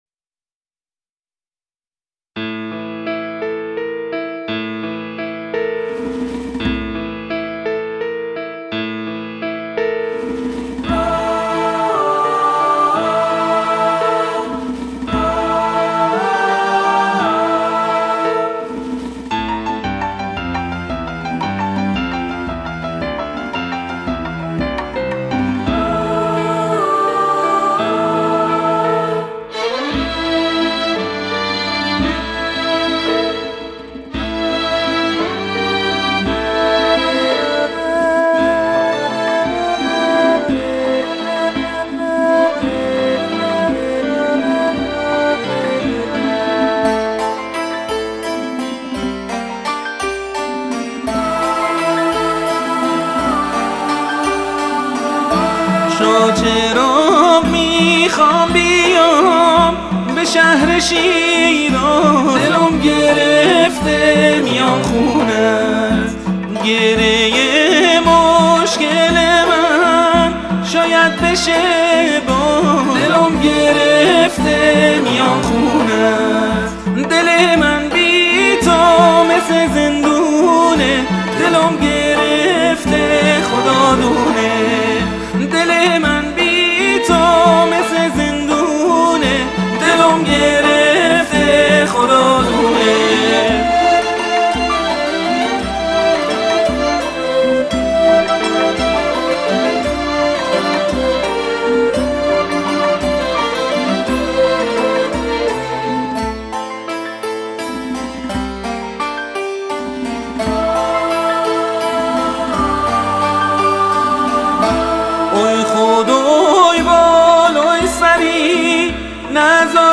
دانلود آهنگ محلی شیرازی